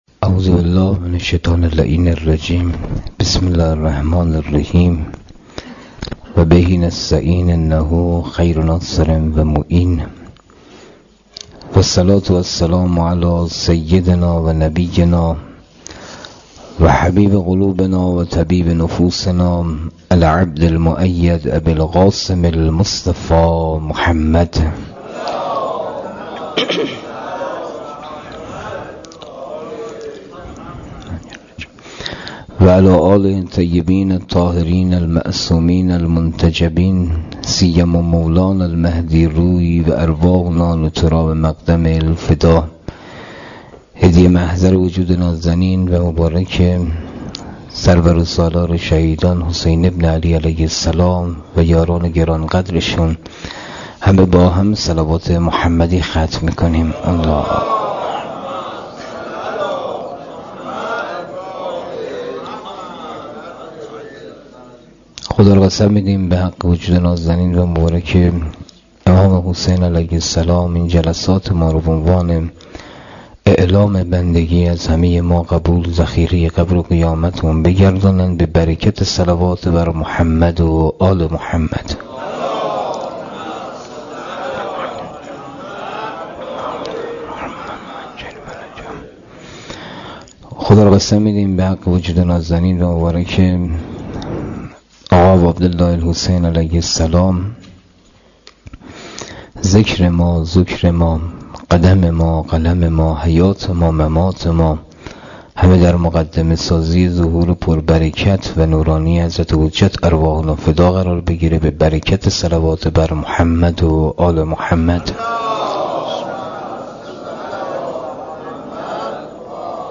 مسجد حضرت خدیجه